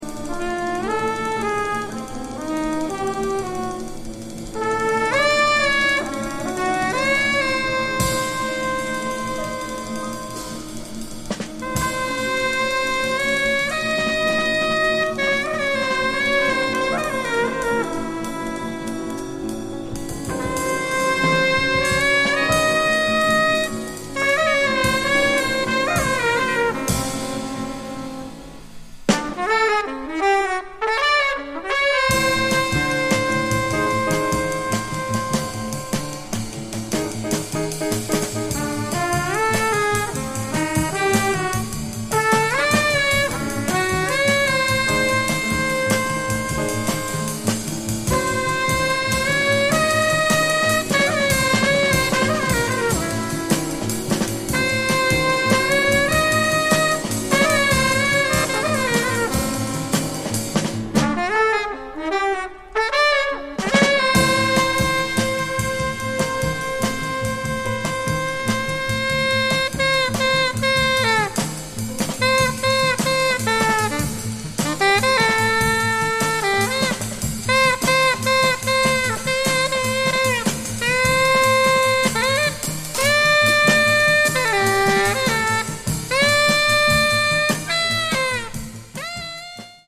Polish Jazz
Stereo reissue.